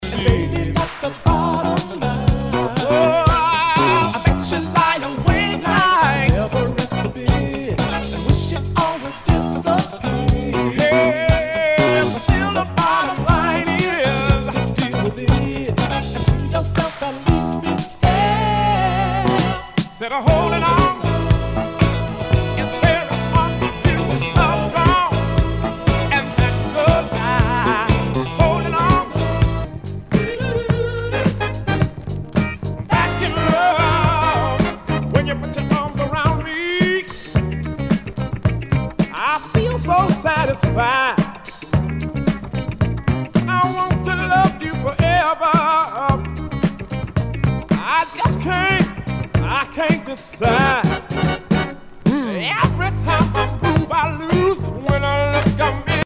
SOUL/FUNK/DISCO
ジャケにスレキズ、ヨゴレ抜けあり。全体にチリノイズが入ります